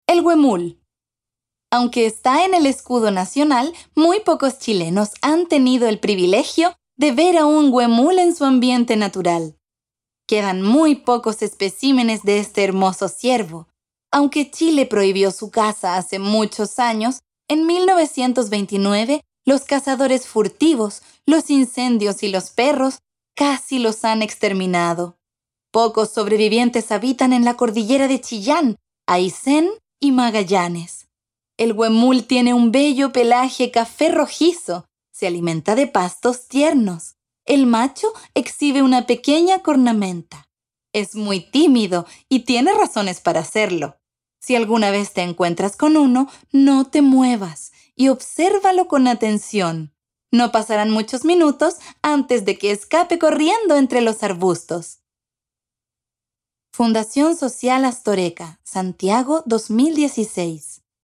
Audiolibro - Extracto Tomo 2